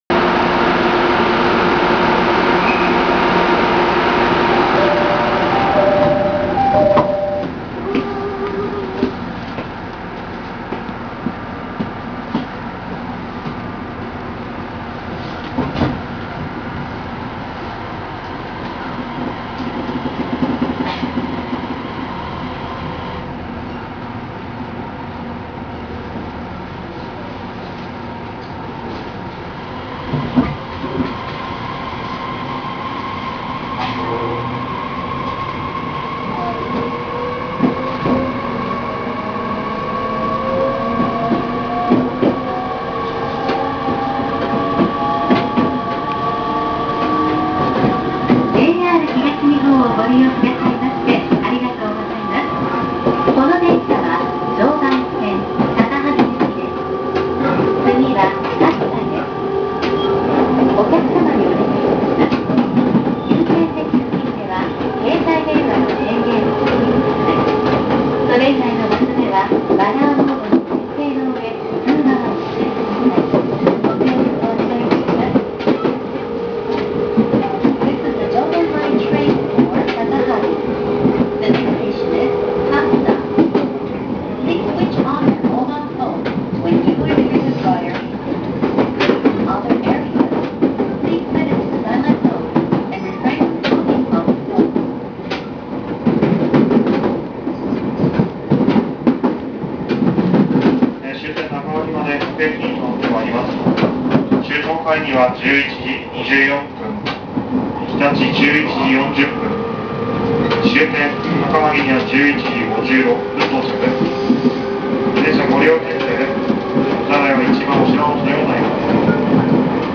・E501系(東芝IGBT車)走行音
【常磐線】水戸→勝田（5分56秒：1.88MB）
JR東としては東芝のモーターは珍しいのかもしれませんが、音自体はそんなに特徴的な物でもありません。自動放送も設置されているので、特徴こそないものの「今の車両」らしくなっています。
K754IGBT_mito-katsuta.WAV